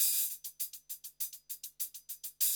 HIHAT LOP5.wav